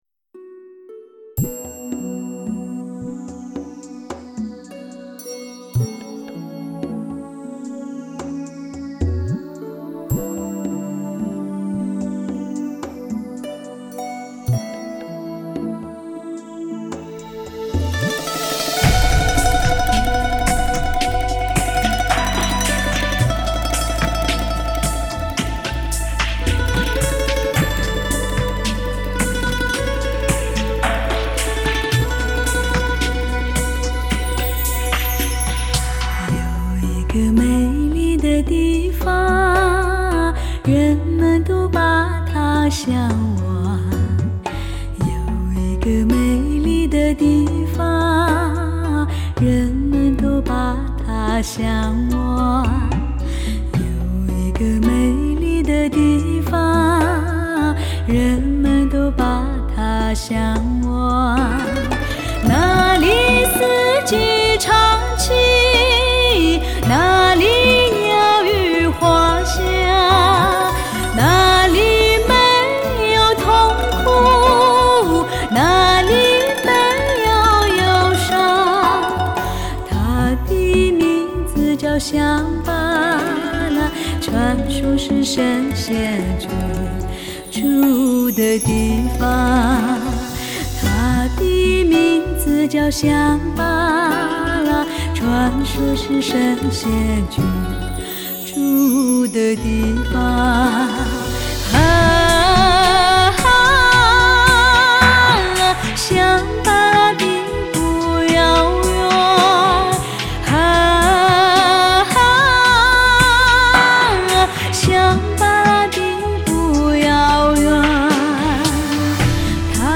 ·民族色彩的再次延续 再显超自然的淳朴与真实
·原生态的自然声音 探访人间最后秘境